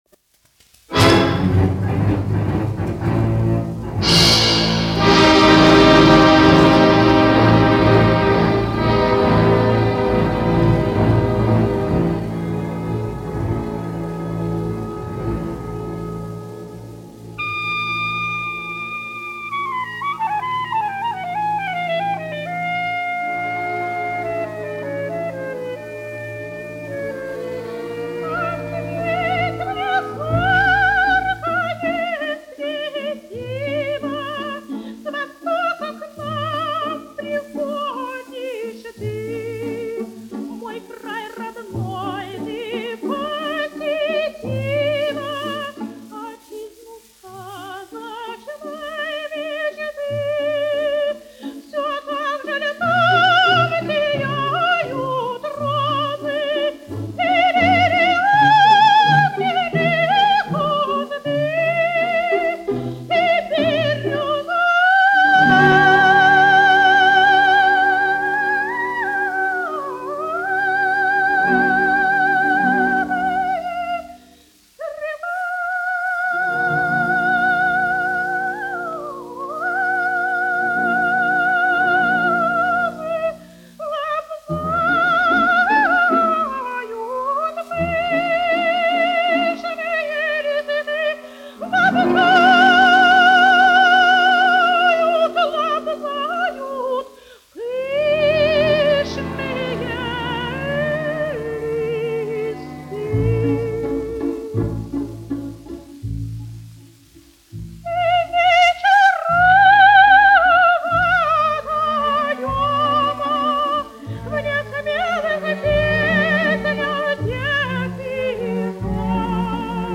1 skpl. : analogs, 78 apgr/min, mono ; 25 cm
Operas--Fragmenti
Skaņuplate